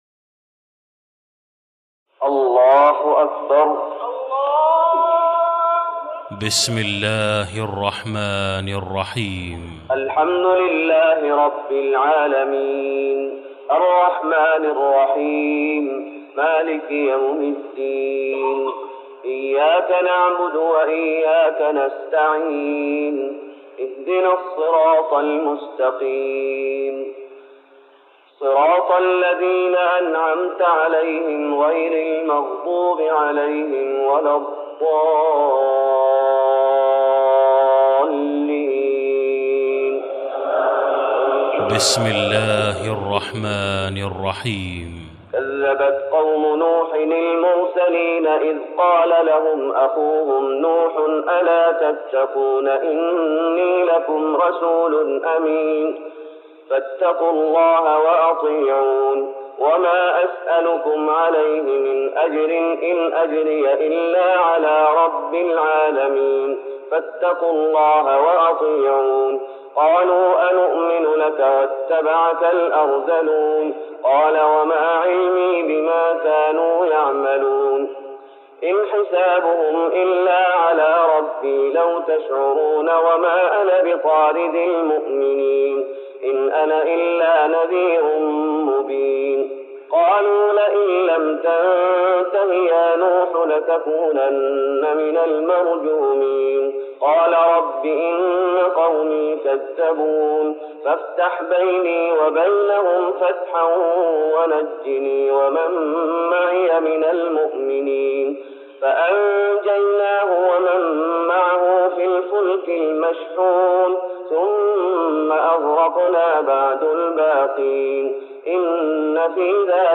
تراويح رمضان 1414هـ من سورة الشعراء (105-227) Taraweeh Ramadan 1414H from Surah Ash-Shu'araa > تراويح الشيخ محمد أيوب بالنبوي 1414 🕌 > التراويح - تلاوات الحرمين